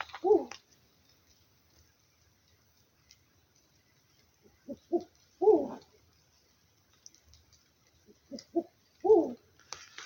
Lechuza Negra (Strix huhula)
Nombre en inglés: Black-banded Owl
Localidad o área protegida: Rp83 - Loma Chata
Condición: Silvestre
Certeza: Filmada, Vocalización Grabada
Voz-009_lechuza-negra.mp3